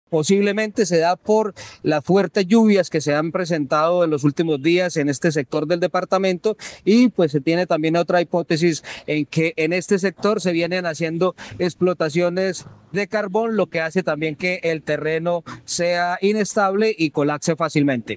Director de la Unidad de Gestión del Riesgo de Santander, Eduard Sánchez